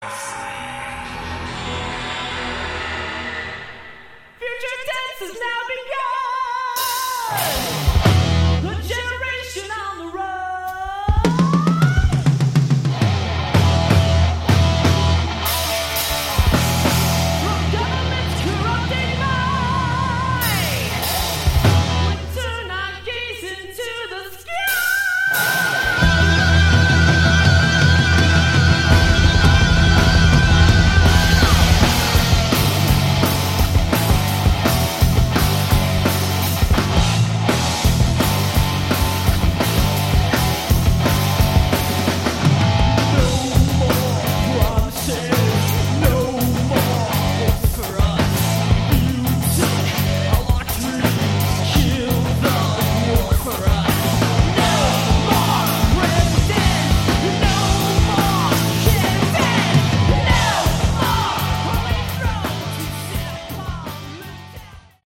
Category: Hard Rock / Melodic Metal